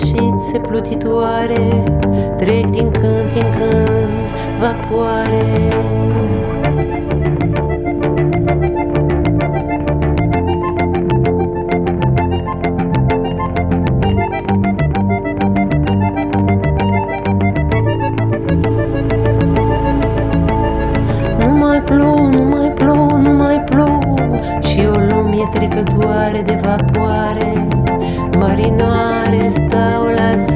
Chansons francophones